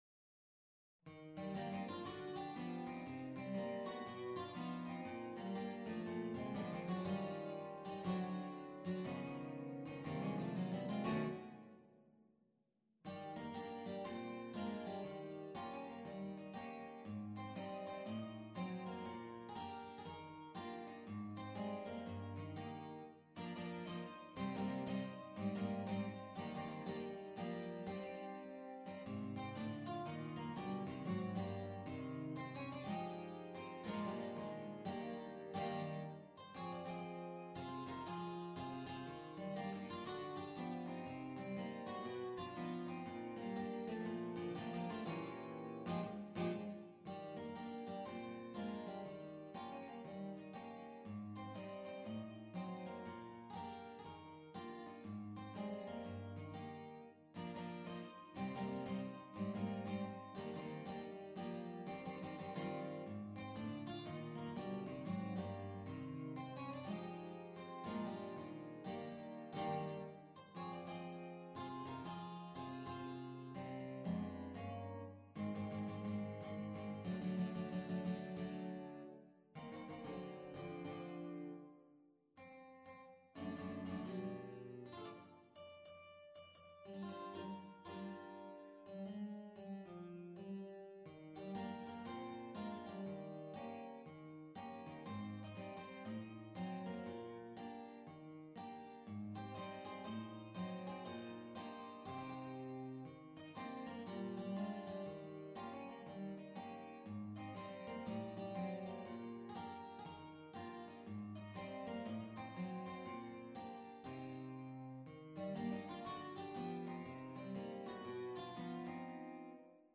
Guitar Quartet – Intermediate Level
MIDI Mockup Recording